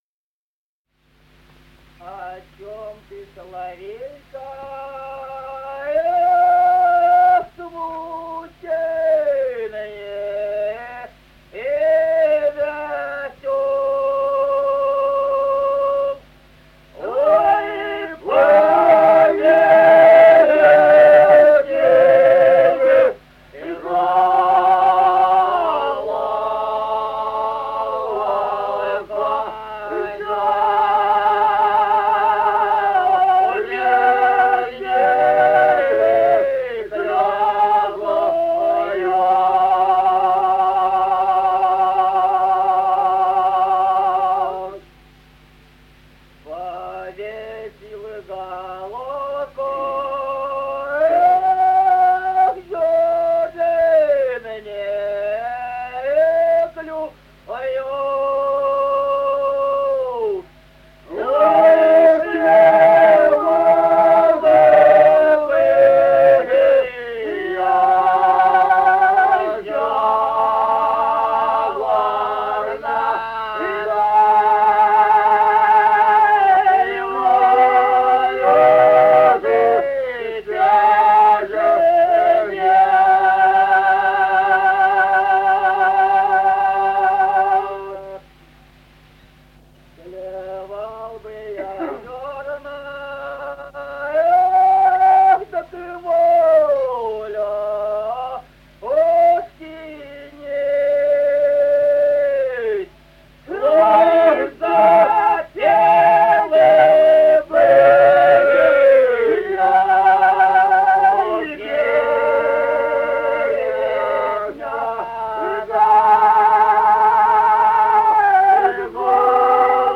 Музыкальный фольклор села Мишковка «А чом ты, соловейка», лирическая.